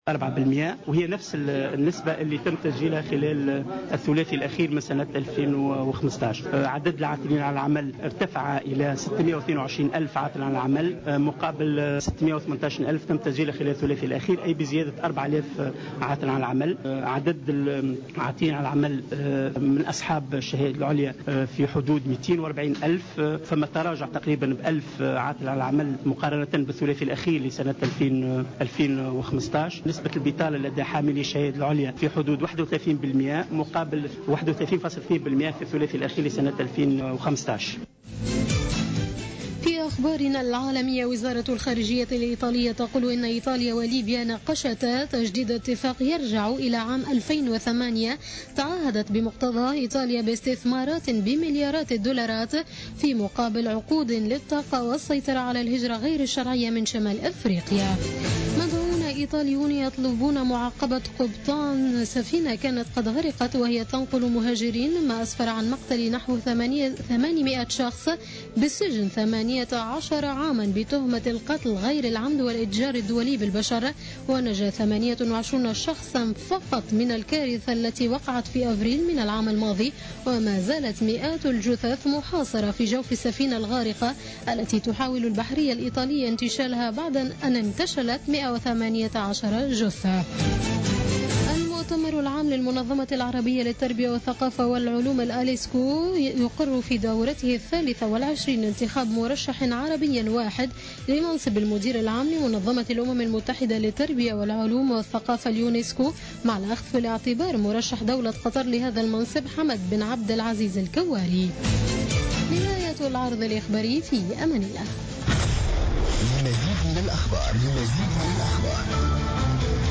نشرة أخبار منتصف الليل ليوم الاربعاء 18 ماي 2016